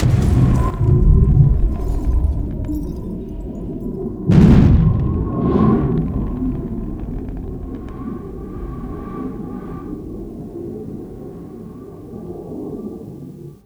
EXPLOSE1  -R.wav